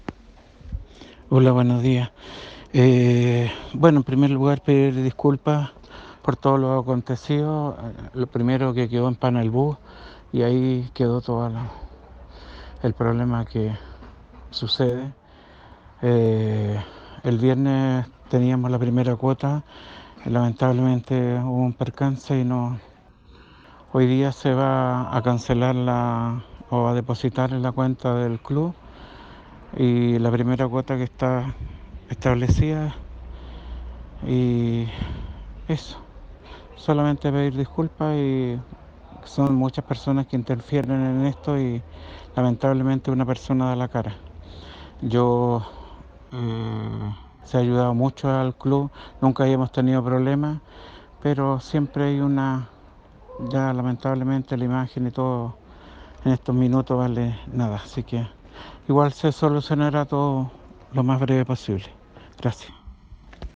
Al intentar contactar al concejal para conversar al respecto, no se encontró disponible, sin embargo envió a CHV Noticias un audio por WhatsApp, en el que señala que “solamente pedir disculpas y son muchas personas las que interfieren en esto y sólo una persona la que da la cara“.